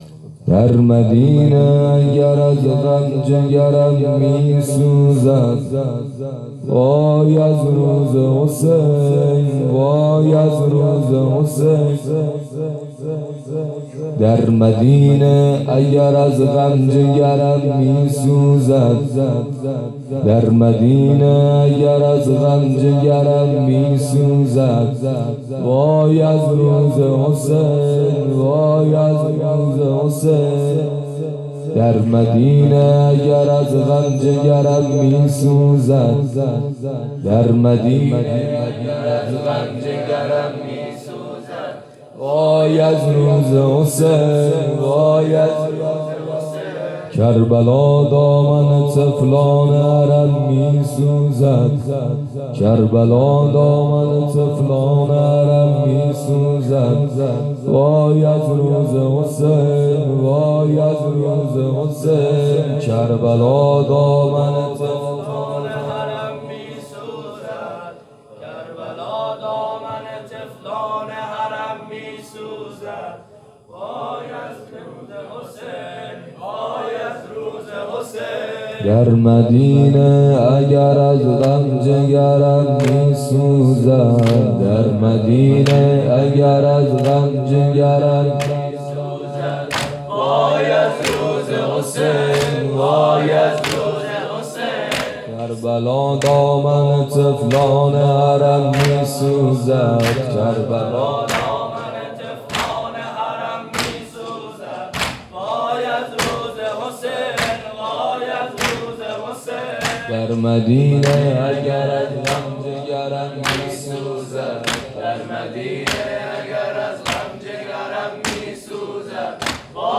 دودمه-در-مدینه-اگر-از-غم-جگرم-میسوزد.mp3